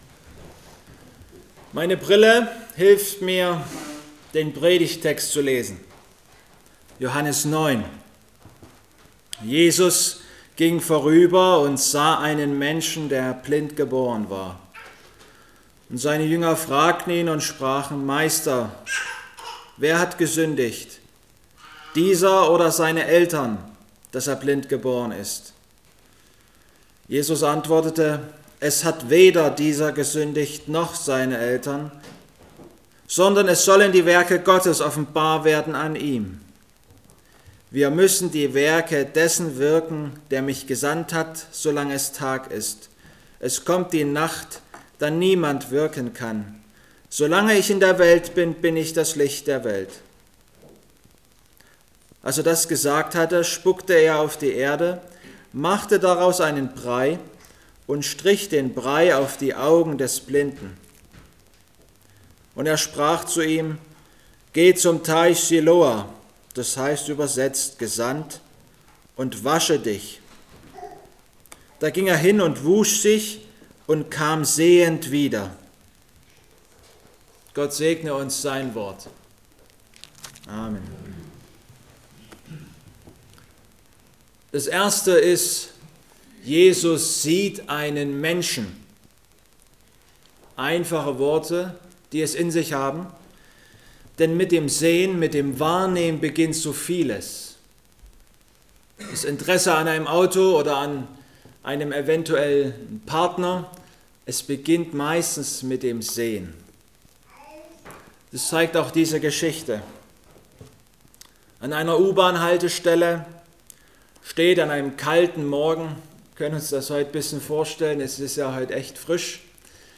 Passage: Johannes 9,1-7 Gottesdienstart: Predigtgottesdienst Obercrinitz Ich habe heute meine Brille mitgebracht.